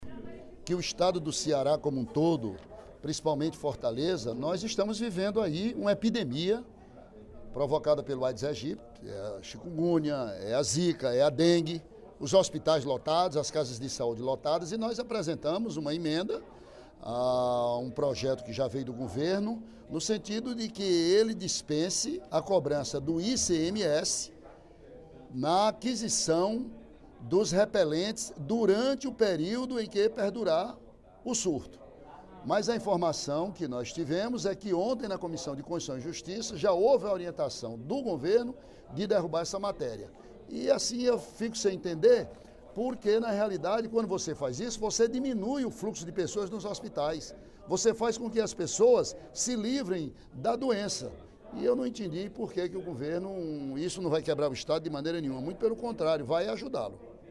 O deputado Ely Aguiar (PSDC) lamentou, no primeiro expediente da sessão plenária desta quarta-feira (17/05), a rejeição - na Comissão  de Constituição, Justiça e Redação (CCJR) - de emenda aditiva apresentada por ele a projeto do Executivo que dispensa o Imposto sobre Circulação de Mercadorias e Serviços (ICMS) sobre a venda de repelentes e inseticidas.